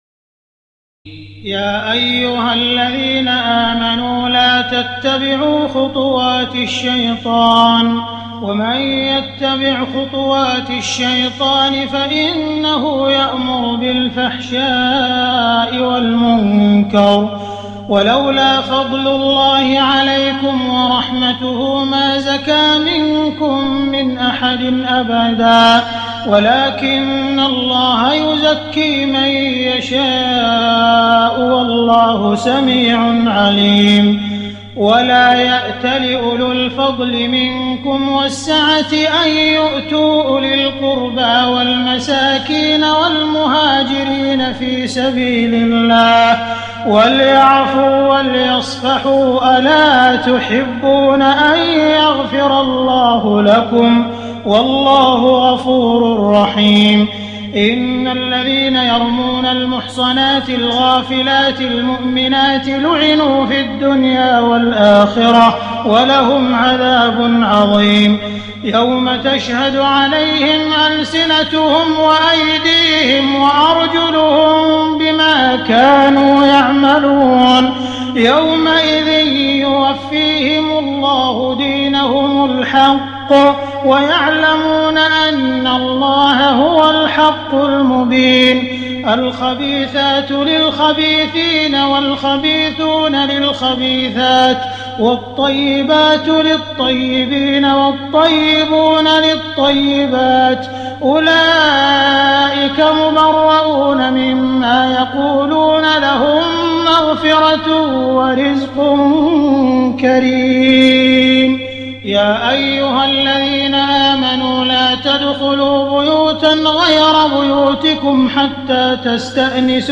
تراويح الليلة السابعة عشر رمضان 1419هـ من سورتي النور (21-64) و الفرقان (1-20) Taraweeh 17 st night Ramadan 1419H from Surah An-Noor and Al-Furqaan > تراويح الحرم المكي عام 1419 🕋 > التراويح - تلاوات الحرمين